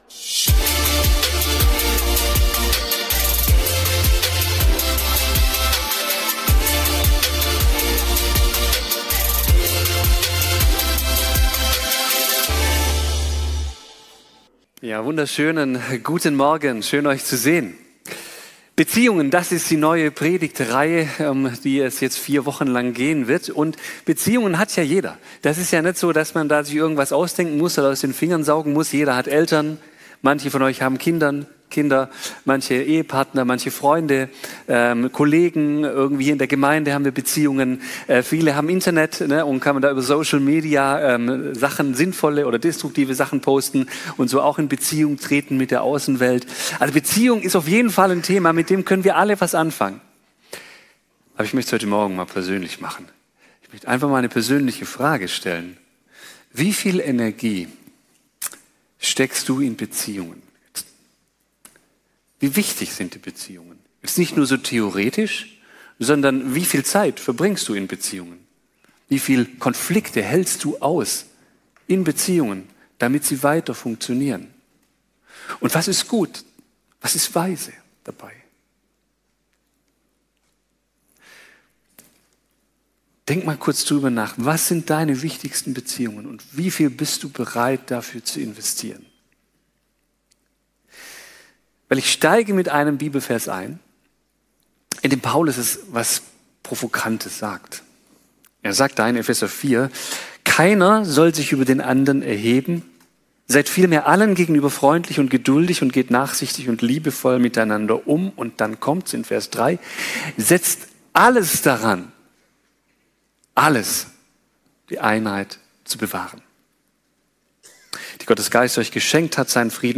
Predigten online